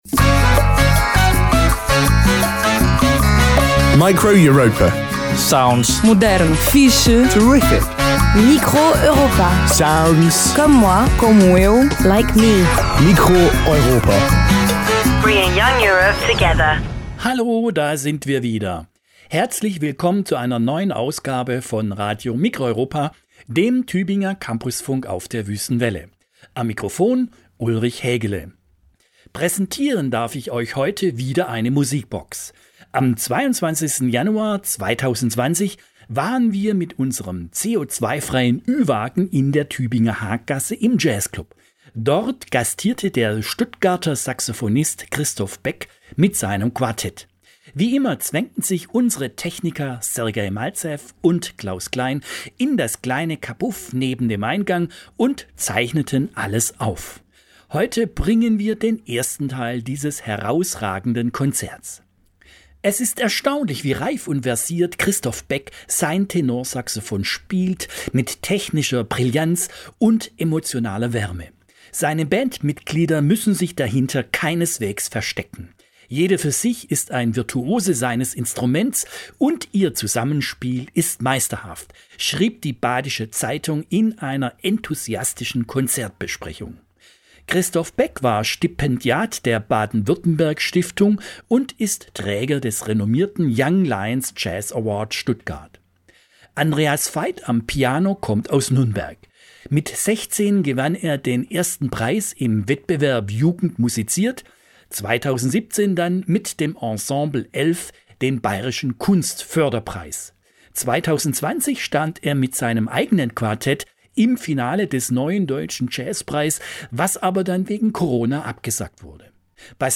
Piano
live im Tübinger Jazzclub
Tenorsaxofon
Bass
Drums
Form: Live-Aufzeichnung, geschnitten